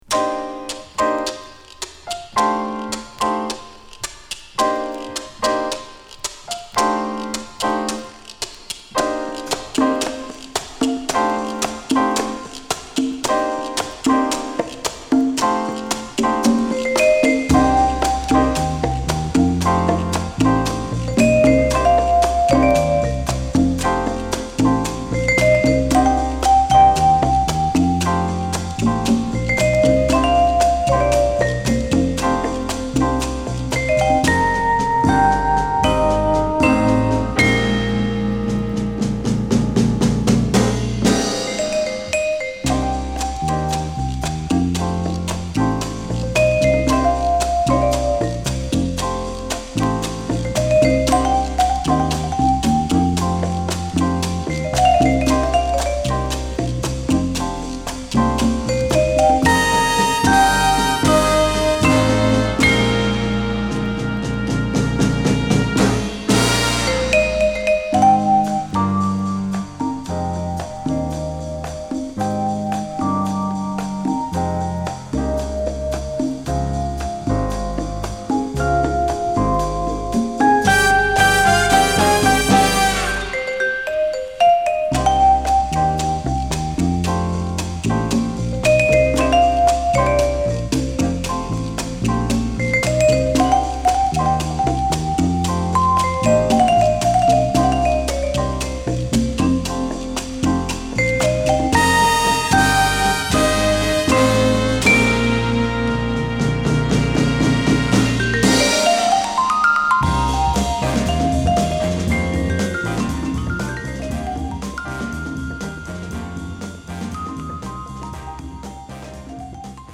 クール&グルーヴィーなグッドチューンを満載した名作！